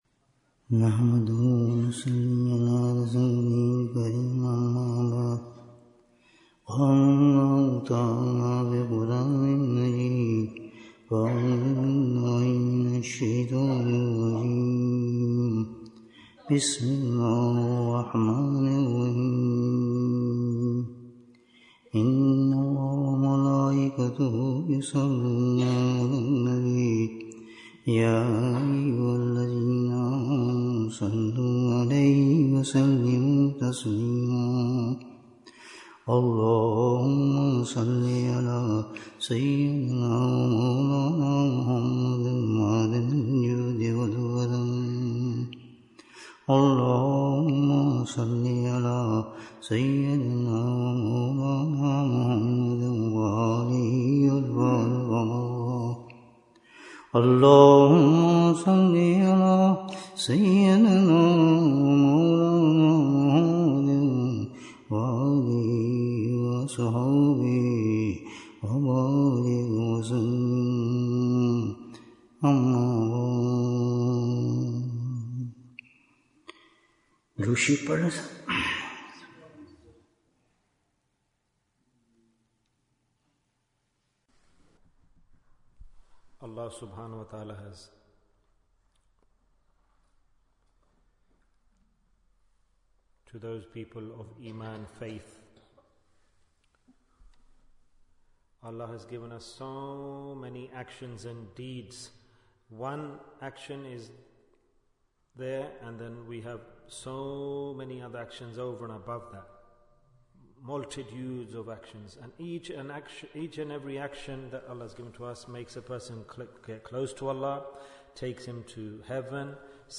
How do we Protect our Tawbah? Bayan, 83 minutes14th November, 2024